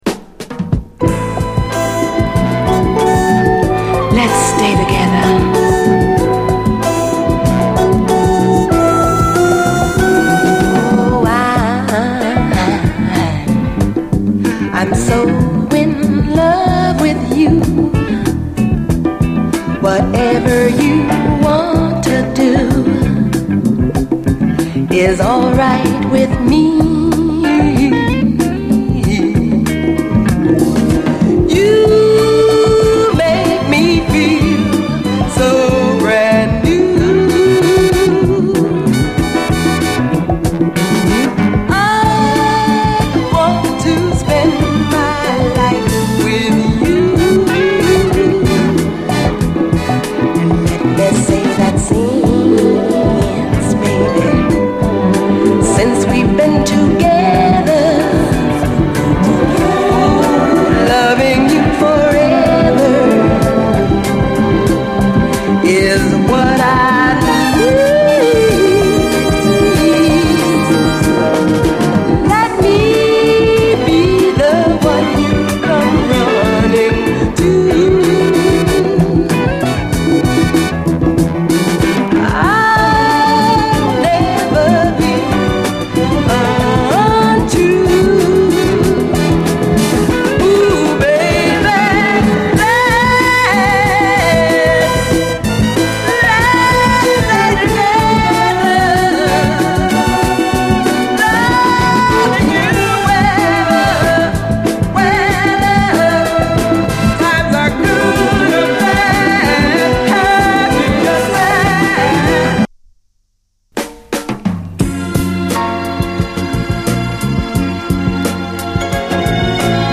SOUL, 70's～ SOUL
A面のみ曲間の無音部分で溝に起因するノイズが時折入りますが曲が始まるとほとんど気になりません。